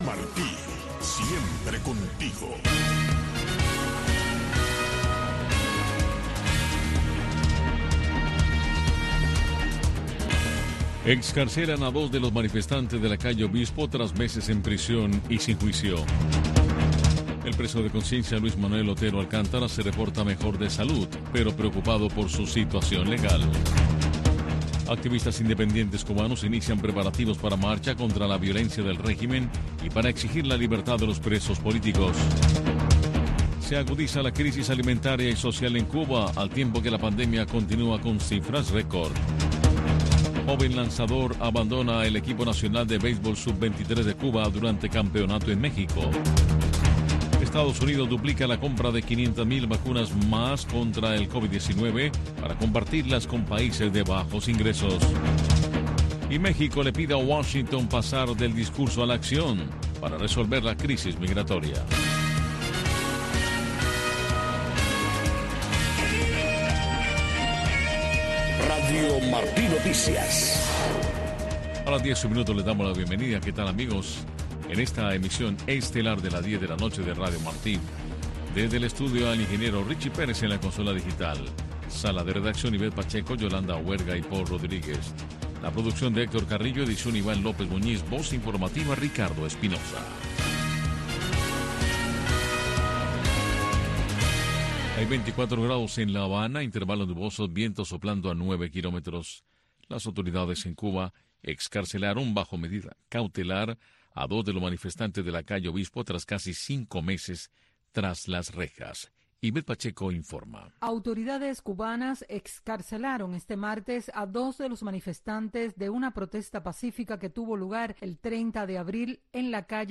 Noticiero de Radio Martí 10:00 PM